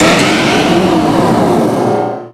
Cri de Méga-Métalosse dans Pokémon Rubis Oméga et Saphir Alpha.
Cri_0376_Méga_ROSA.ogg